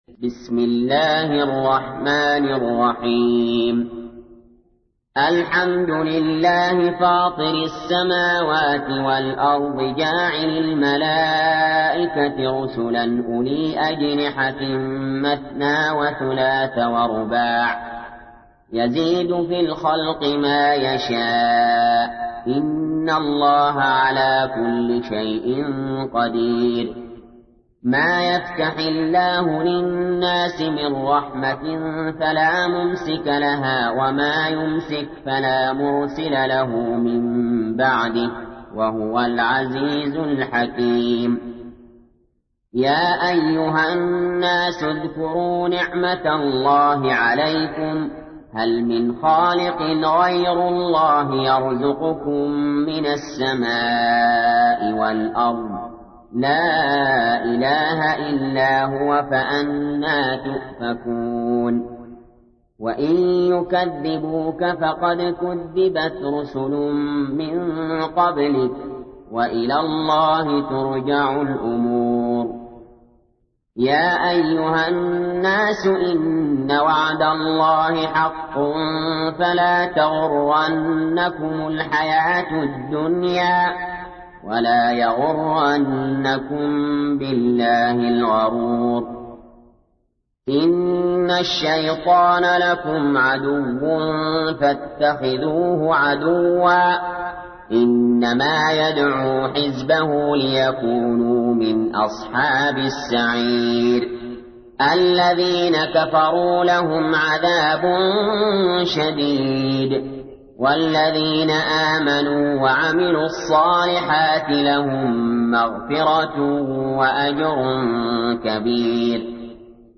تحميل : 35. سورة فاطر / القارئ علي جابر / القرآن الكريم / موقع يا حسين